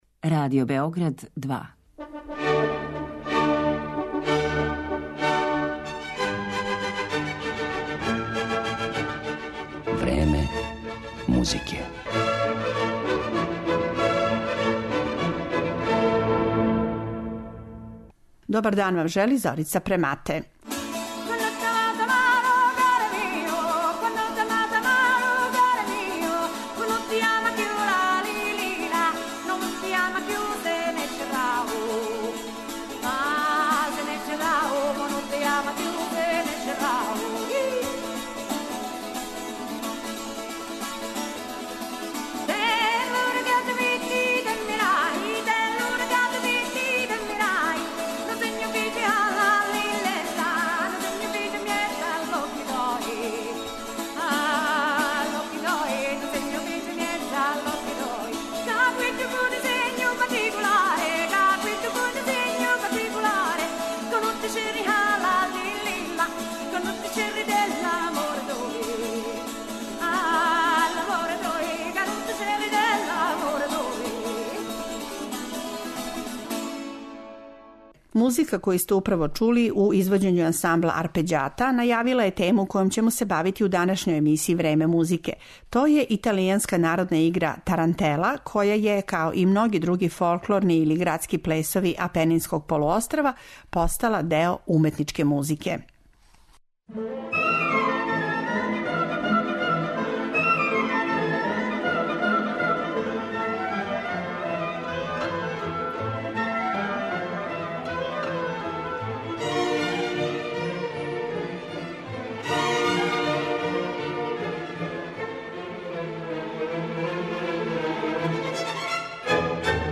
Такође, емитоваћемо и тарантеле које су, између осталих, компоновали Менделсон, Росини, Шопен, Вјењавски, Лист, Рахмањинов и Чајковски.